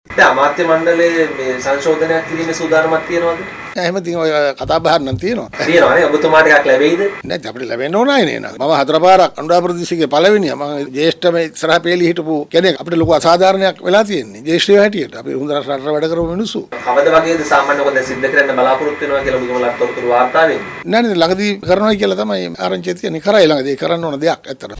ඔහු මෙම අදහස් පළ කළේ අද කොළඹ පැවති මාධ්‍ය හමුවකට එක් වෙමින්.